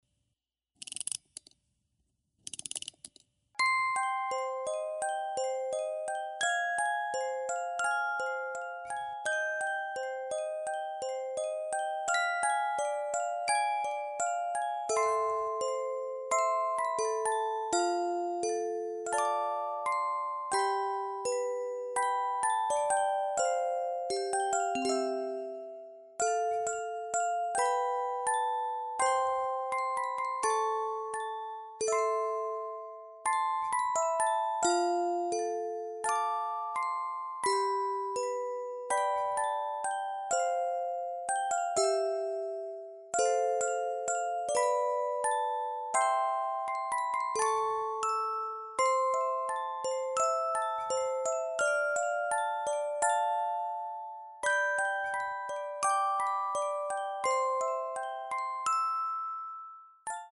Завели и услышали прекрасную мелодию